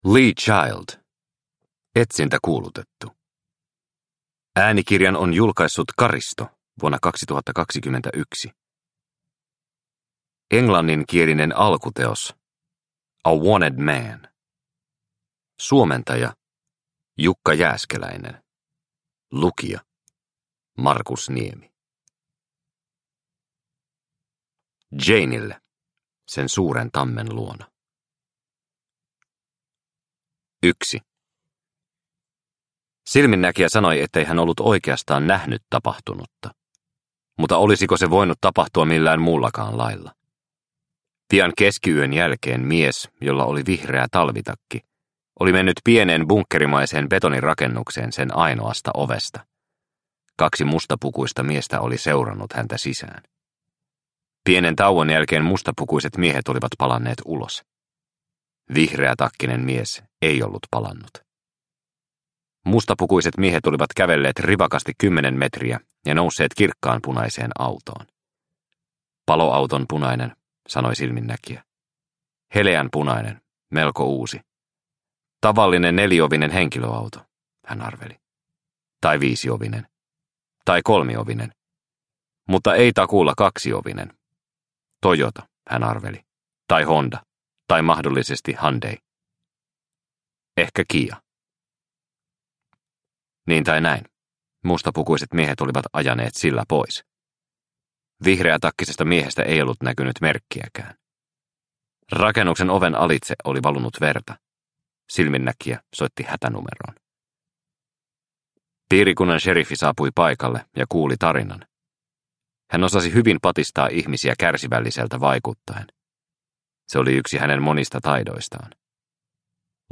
Etsintäkuulutettu – Ljudbok – Laddas ner